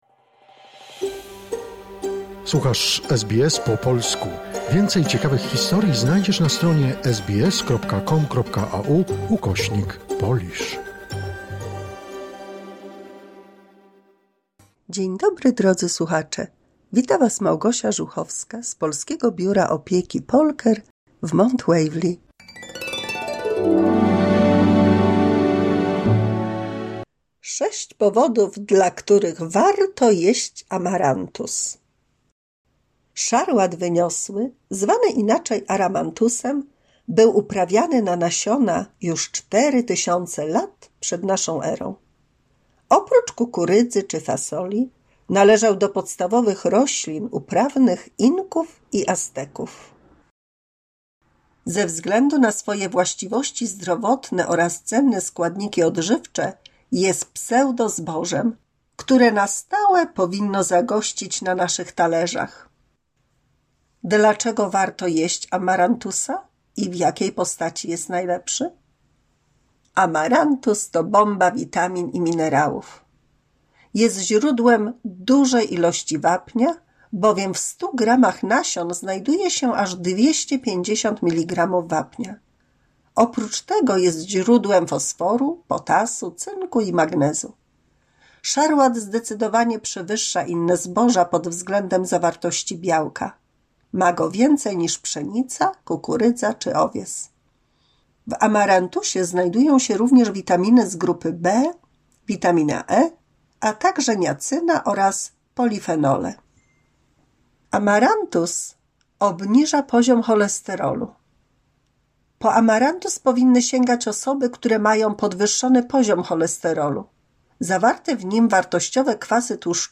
mini słuchowisko